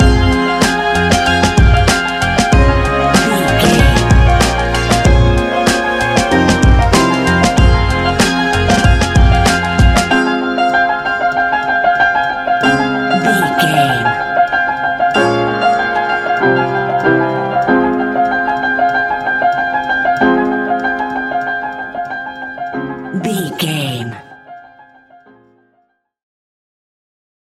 Ionian/Major
F♯
laid back
Lounge
sparse
new age
chilled electronica
ambient